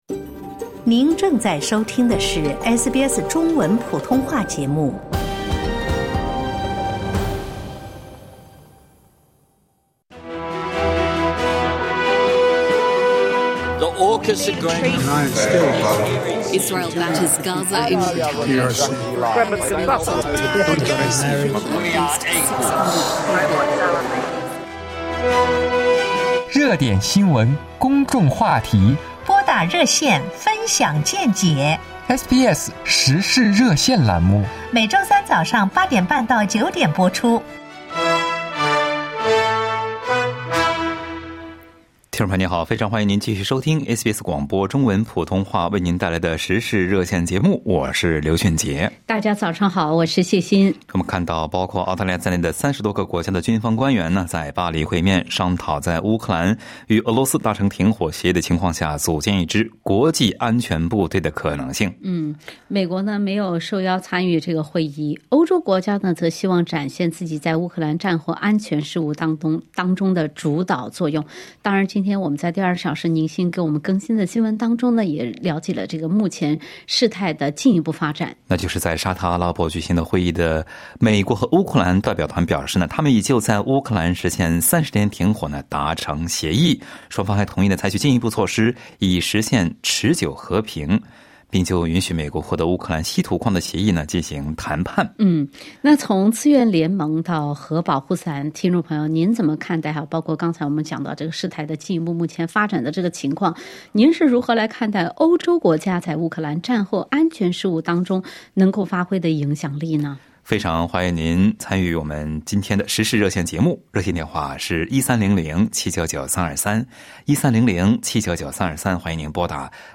在SBS《时事热线》节目中，听友们就欧洲国家在乌克兰战后安全事务中的影响力发表了各自的看法。